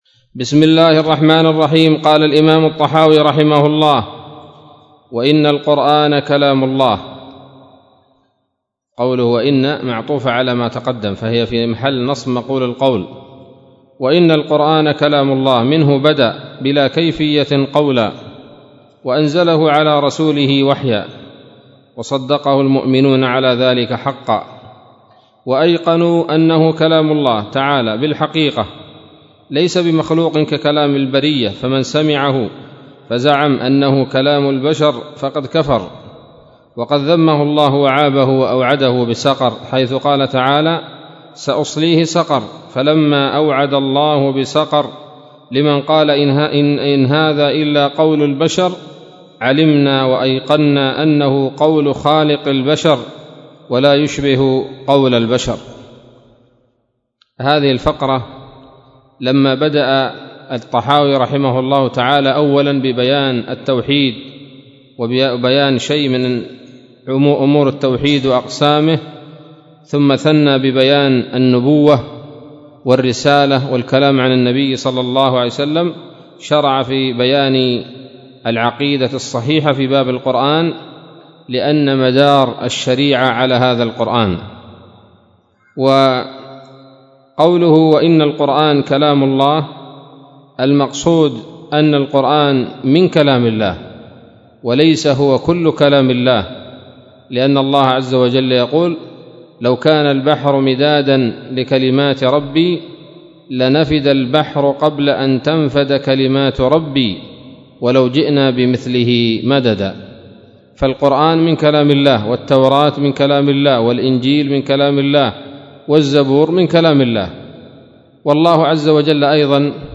الدرس الحادي عشر من شرح العقيدة الطحاوية